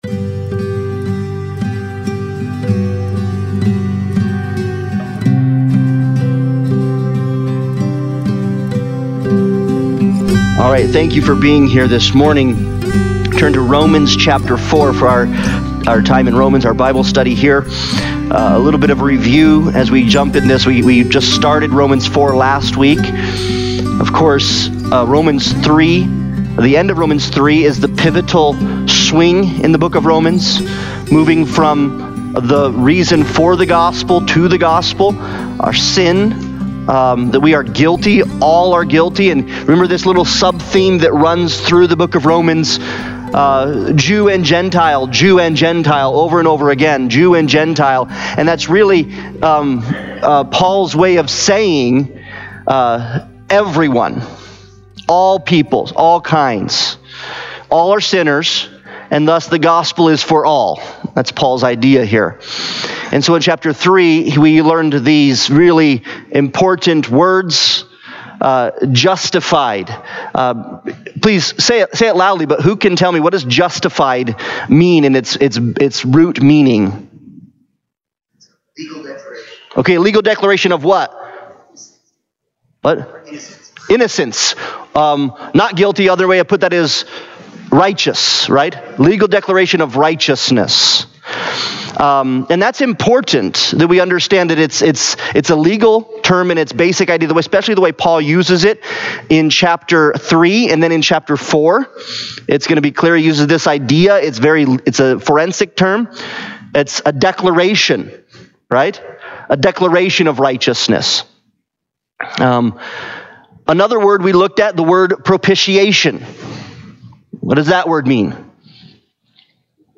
Romans Analysis Passage: Romans 4:1-15 Service Type: Sunday Bible Study « Christ